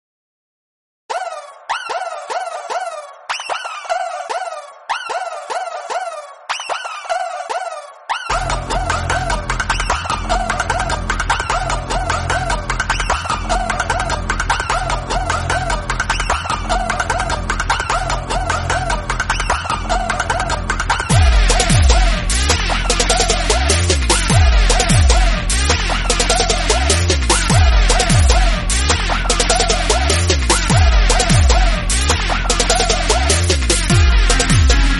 BGM Instrumental Ringtone